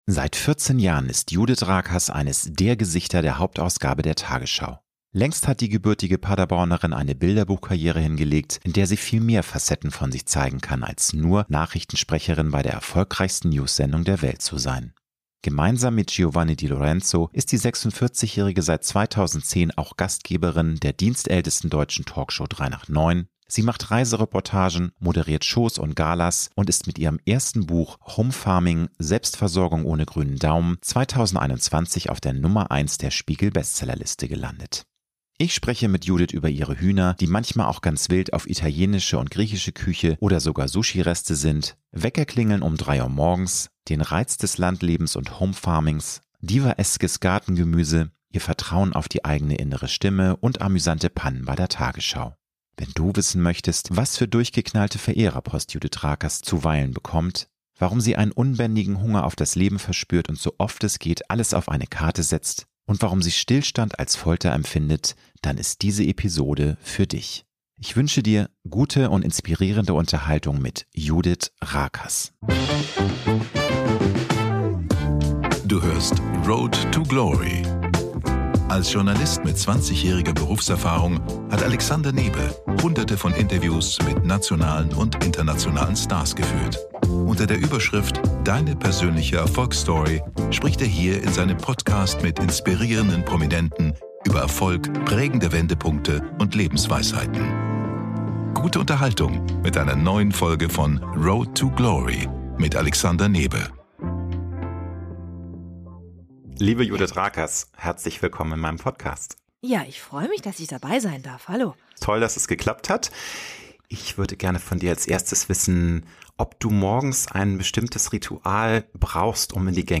Ich spreche mit Judith über ihre Hühner, die manchmal auch ganz wild auf italienische und griechische Küche oder sogar Sushi sind, Weckerklingeln um drei Uhr morgens, den Reiz des Landlebens und Homefarmings, divaeskes Gartengemüse, ihr Vertrauen auf die eigene innere Stimme und amüsante Pannen bei der Tagesschau.